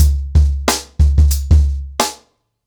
TrackBack-90BPM.51.wav